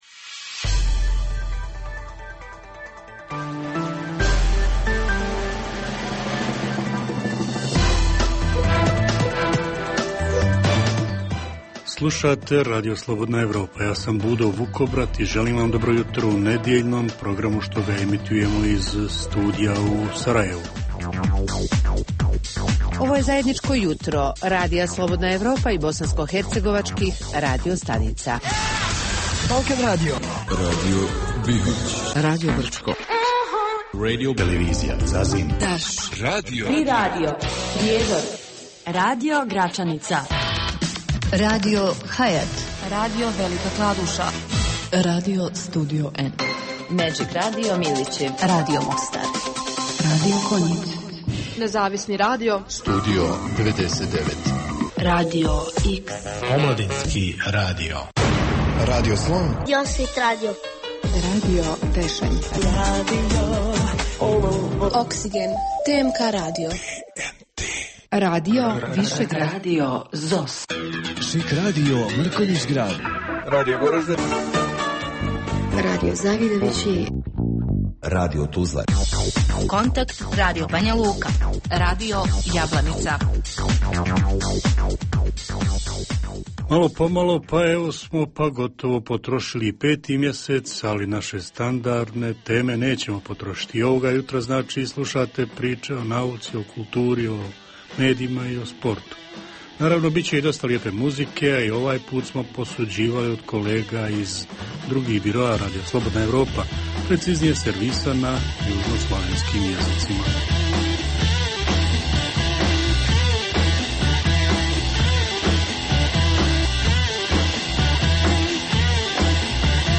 Jutarnji program namijenjen slušaocima u Bosni i Hercegovini. Sadrži novosti iz svijeta nauke, medicine, visokih tehnologija, sporta, filma i muzike.